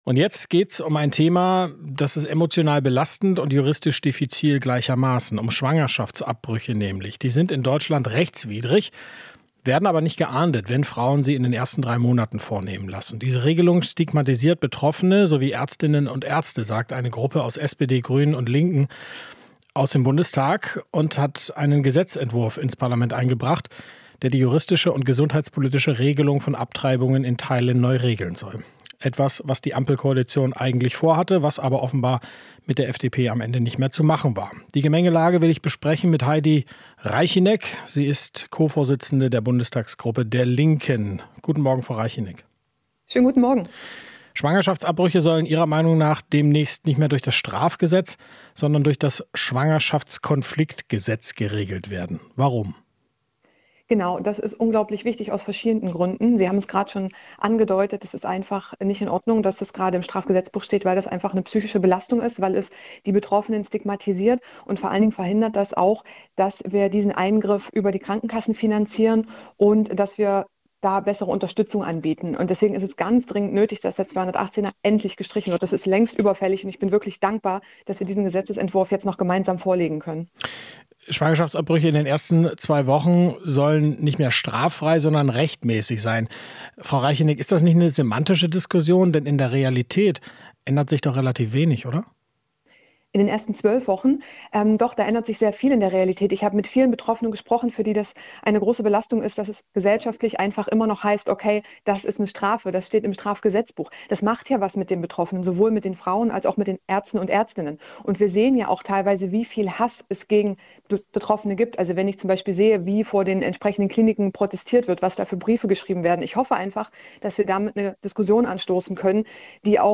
Interview - Reichinnek (Linke): §218 muss endlich abgeschafft werden